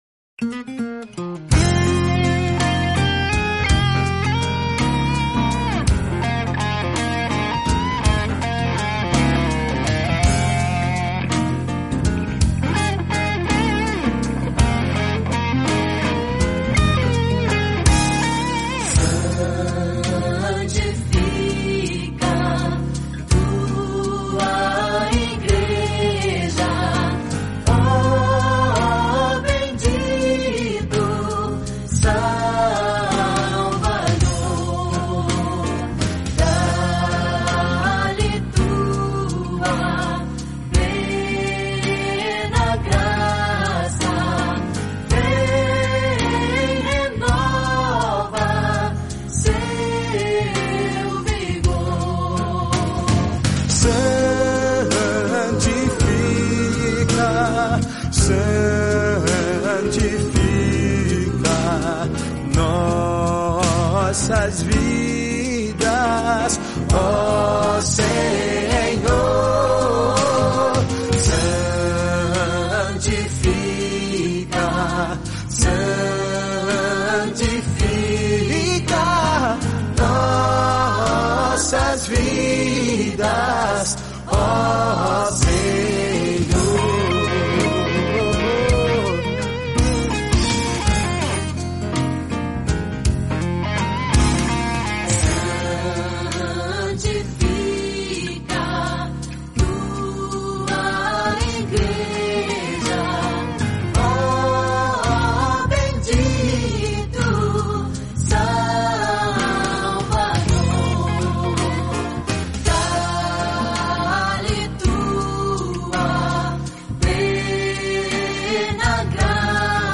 Em diversas oportunidades, participei, ao vivo, de programas da 'Rádio Você', emissora que fazia parte do grupo jornalístico 'O Liberal', de nossa cidade, Americana.